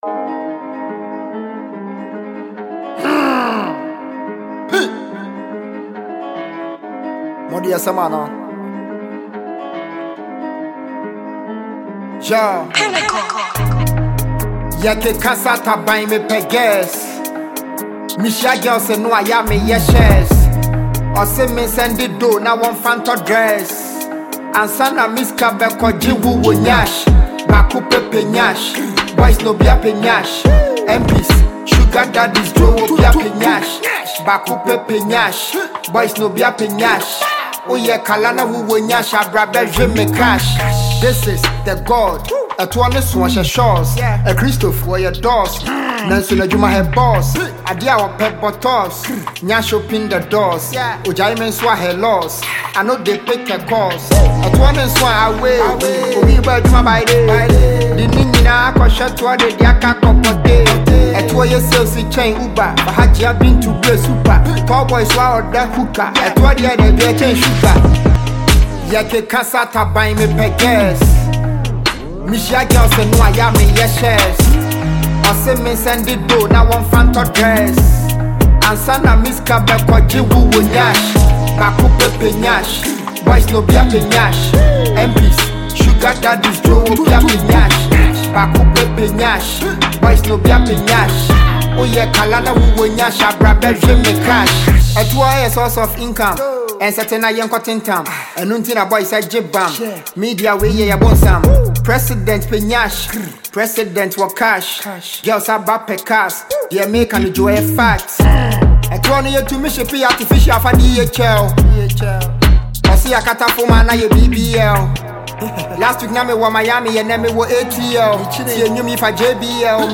Afrobeats
so be sure to give it a listen and enjoy the groovy vibes!